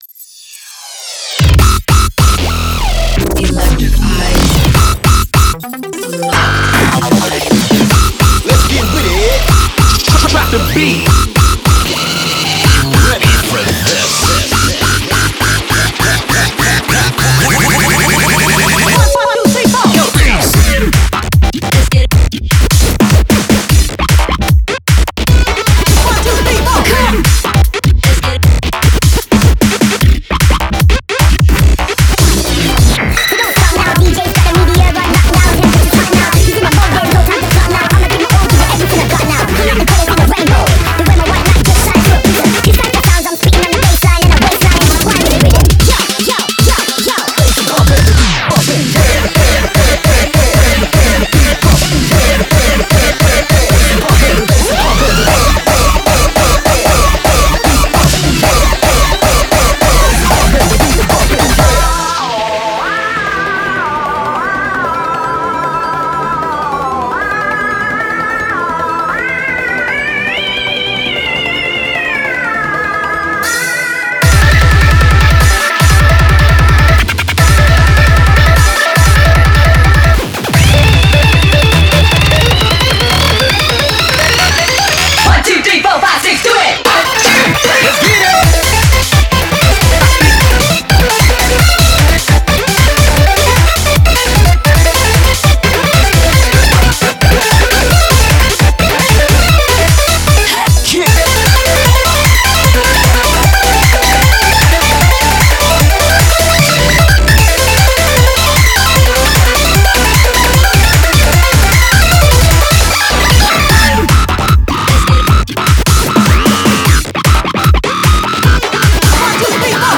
BPM152
Audio QualityPerfect (High Quality)
Comments[HI-TECH FULL ON]